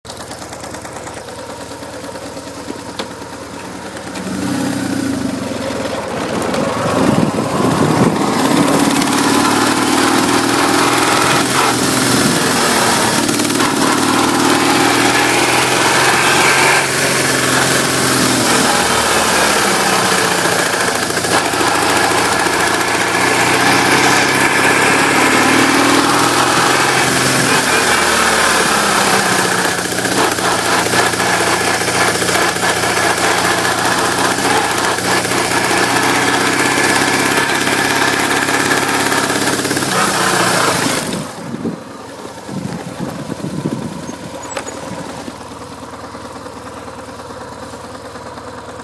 Alors que tout allait bien après la dernière vidange de boîte de vitesses, elle fait maintenant un bruit de transmission et d'engrenage, un peu comme une vielle Ford-T. Le bruit est parfaitement audible lors du démarrage.
boîte chanteuse
boite-chanteuse.mp3